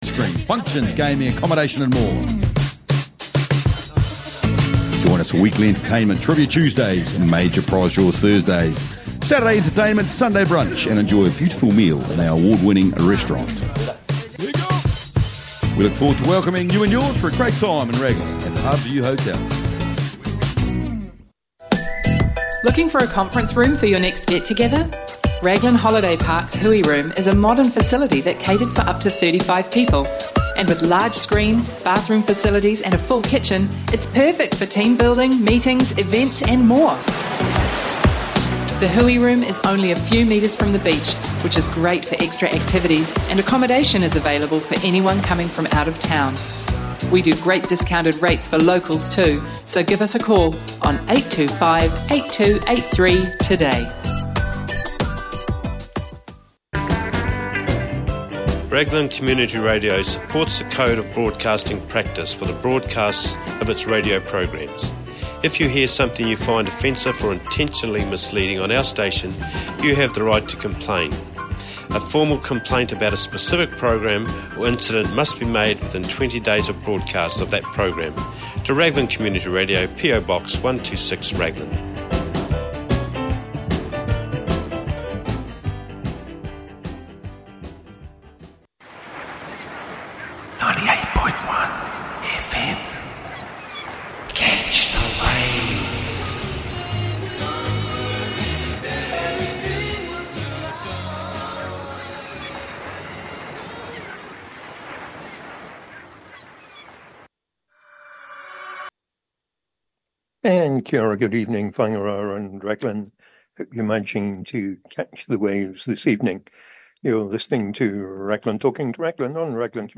Another week of local news and Morning Show interviews